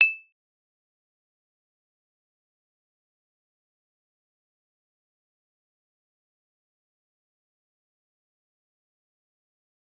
G_Kalimba-E7-f.wav